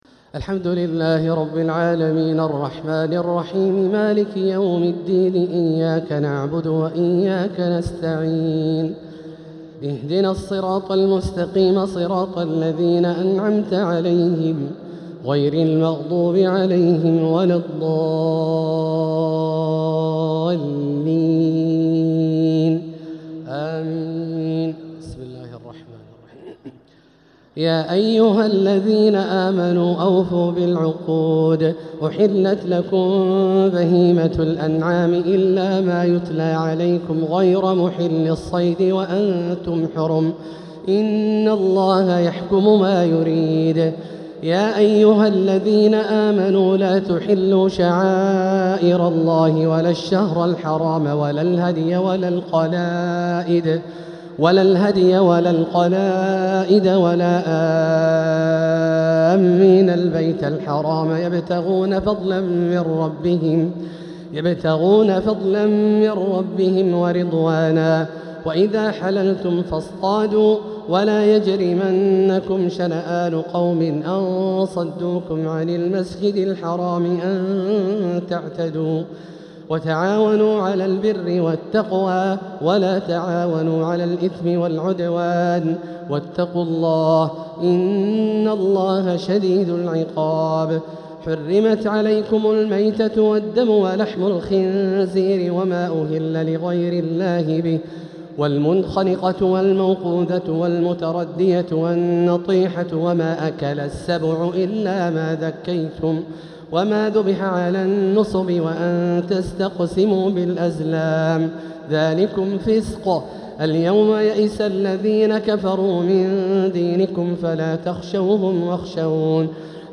بنبراته الشجية يبدع ويستفتح الشيخ د. عبدالله الجهني سورة المائدة | تراويح ليلة 8 رمضان 1447هـ > تراويح 1447هـ > التراويح - تلاوات عبدالله الجهني